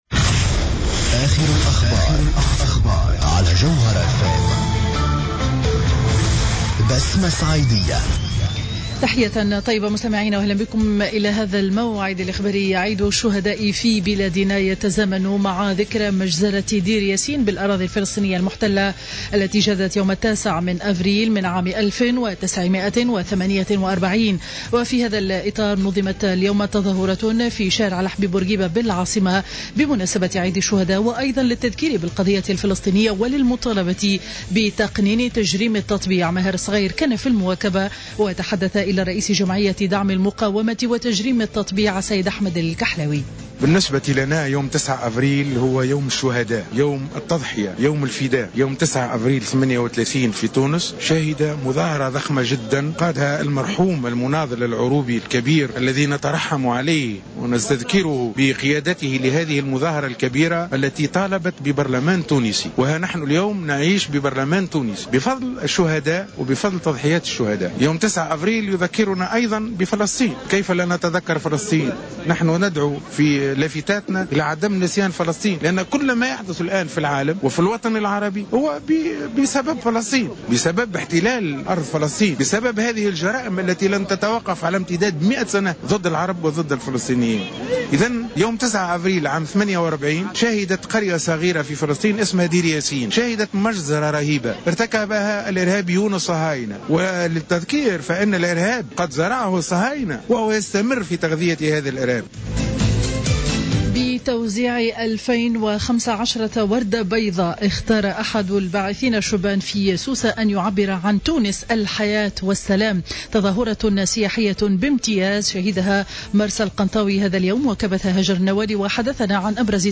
نشرة أخبار منتصف النهار ليوم الخميس 9 أفريل 2015